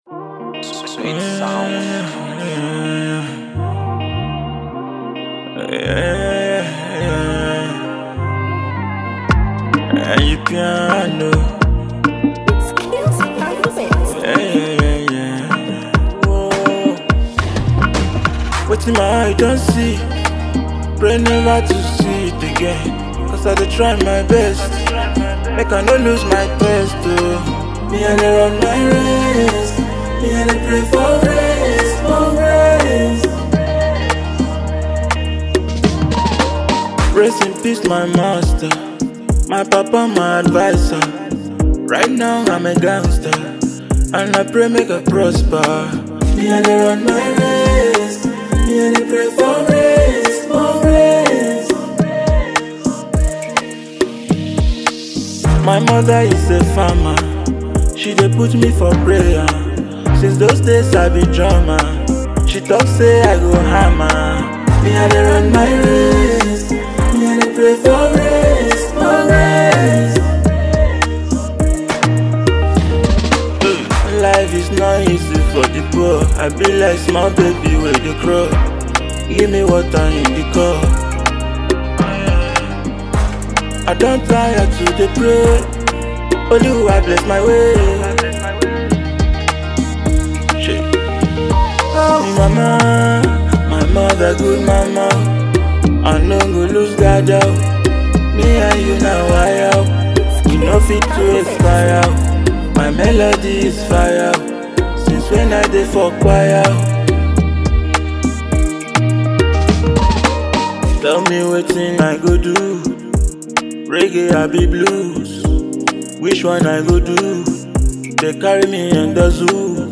Afro High-life and Fusion